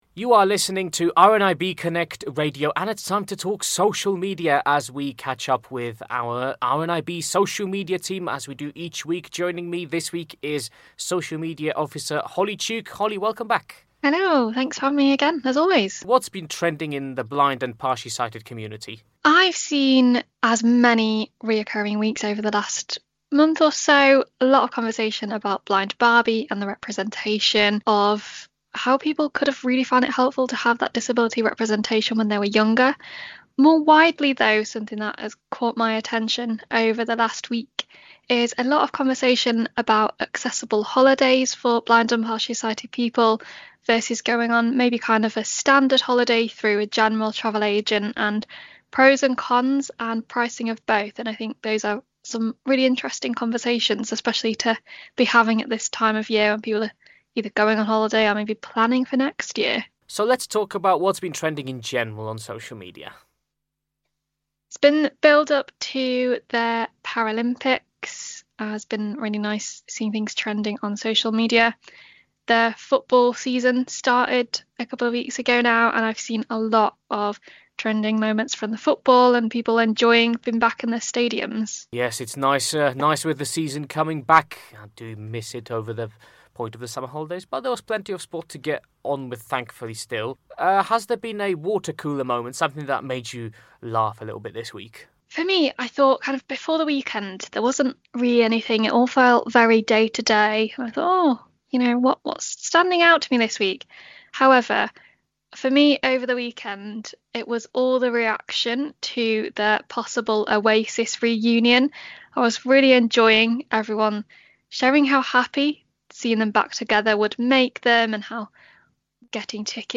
our weekly chat with the RNIB social media team